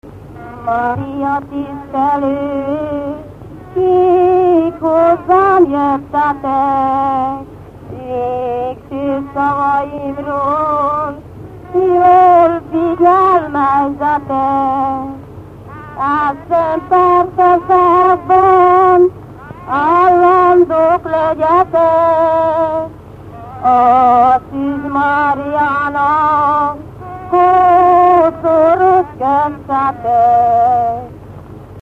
Dunántúl - Tolna vm. - Kéty
ének
Dallamtípus: Lóbúcsúztató - halottas 1
Stílus: 8. Újszerű kisambitusú dallamok